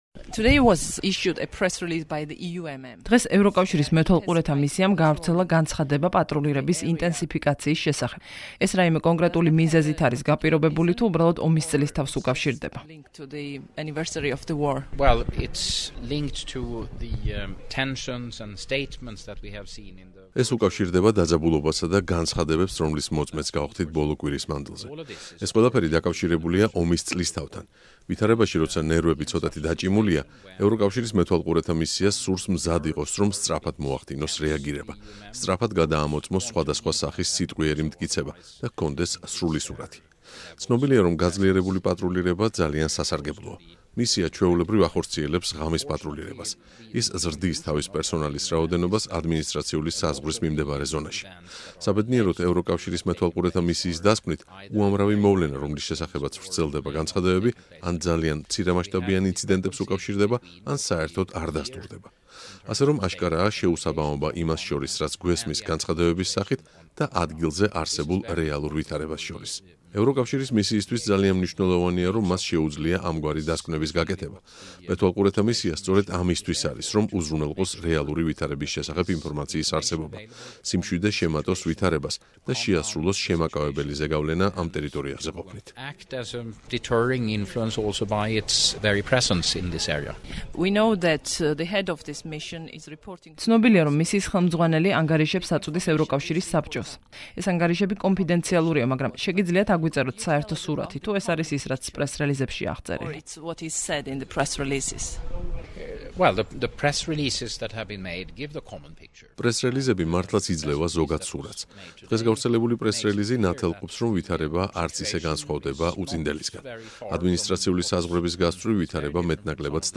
აგვისტოს ომის დაწყებიდან ერთი წლის თავზე რადიო თავისუფლებას ექსკლუზიური ინტერვიუ მისცა სამხრეთ კავკასიაში ევროკავშირის საგანგებო წარმომადგენელმა, რომელიც თბილისში იმყოფება.